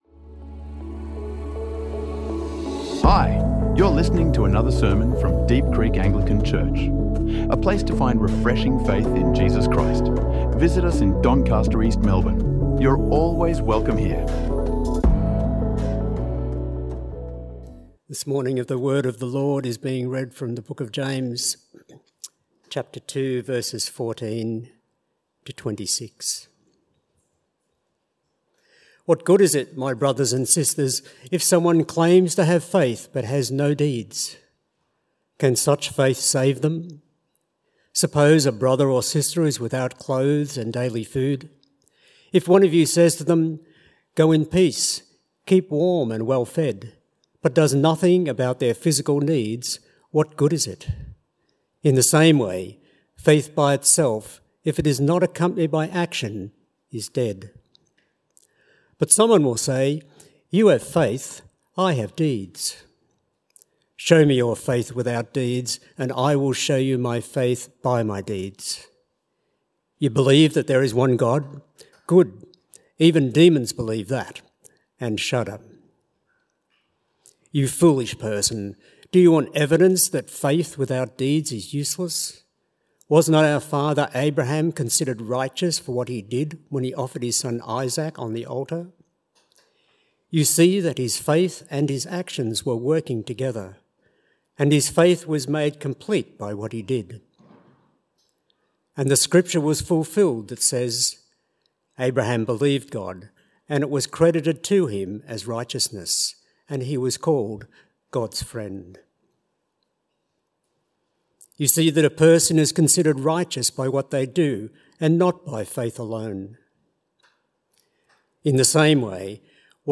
Faith Without Works is Dead | Sermons | Deep Creek Anglican Church